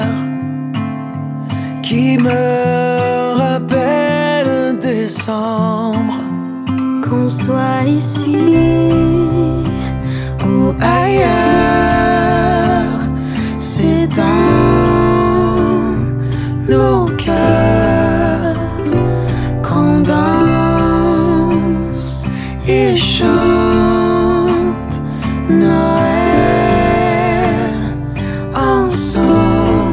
Genre: Musique francophone